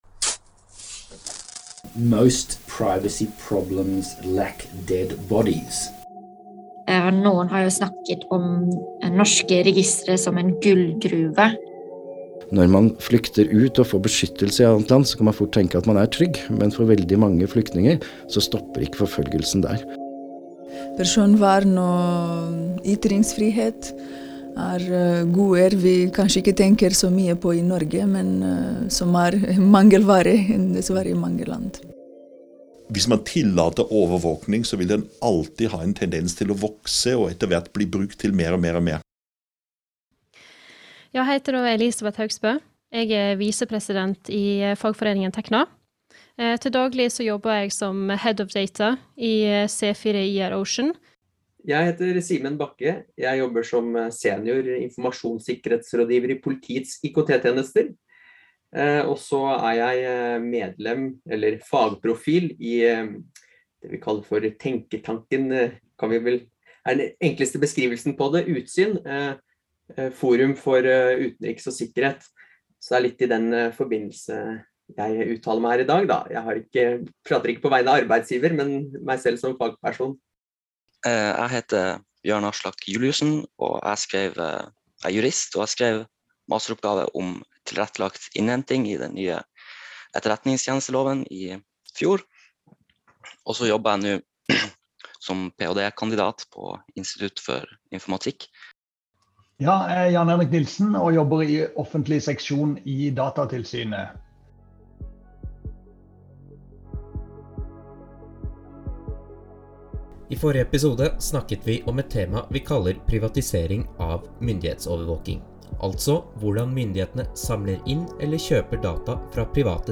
I serien har vi invitert flere forskjellige gjester.
Vær obs: i serien snakkes det kanskje tidvis med utestemme.